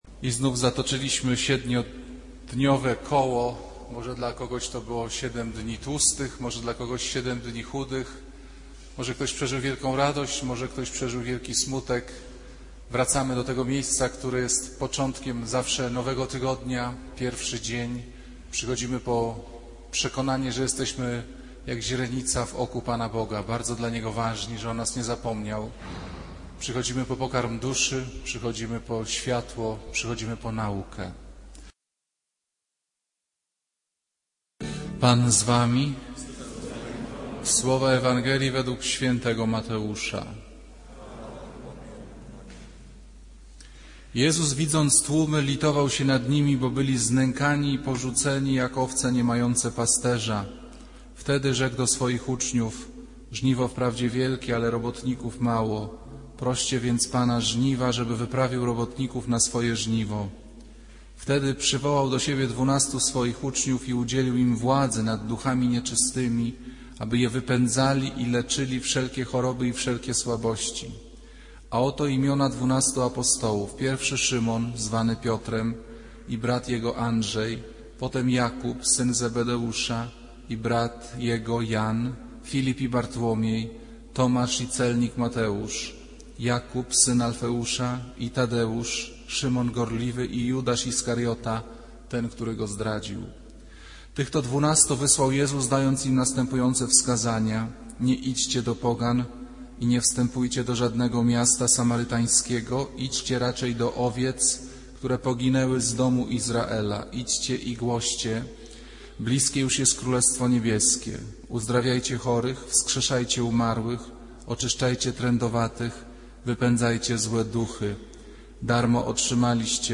Kazanie z 15 czerwca 2008r.
Piotra Pawlukiewicza // niedziela, godzina 15:00, kościół św. Anny w Warszawie « Kazanie z 29 czerwca 2008r.